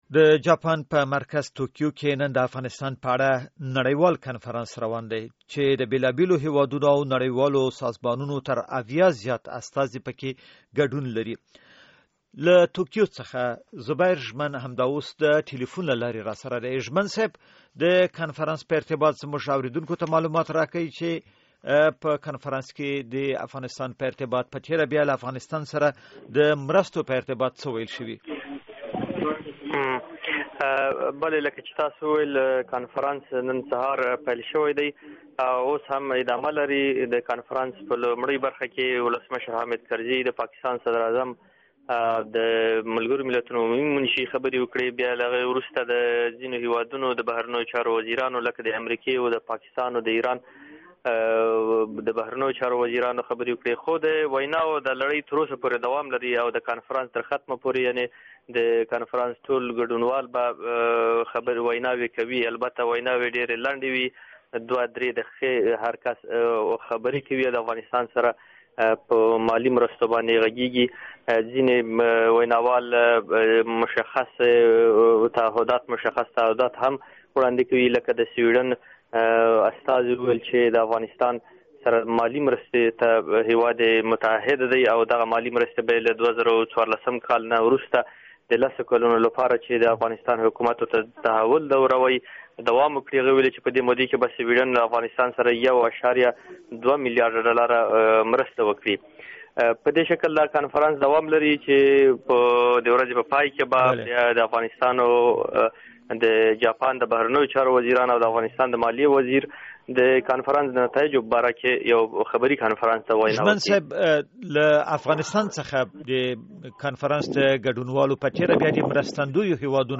په توکیو کې زموږ همکار ژوندی راپور راکوي